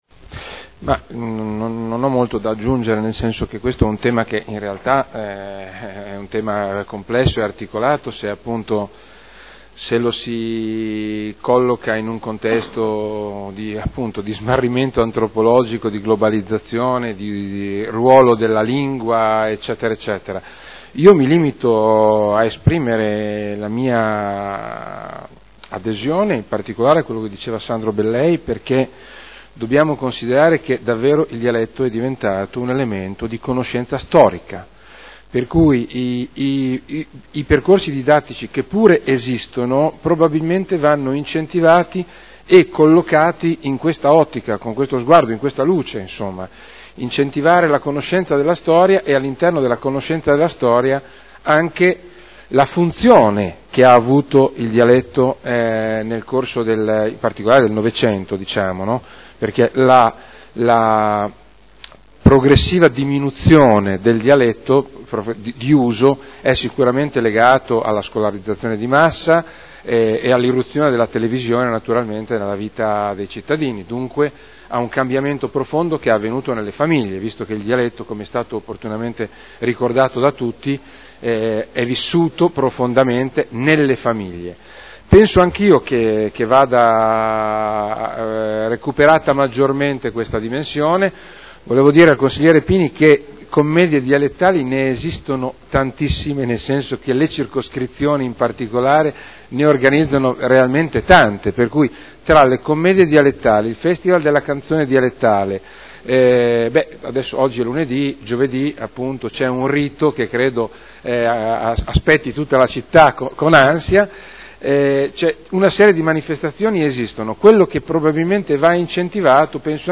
Conclude interrogazione del consigliere Rossi N. (Lega Nord) avente per oggetto: “Cartelli “Mòdna””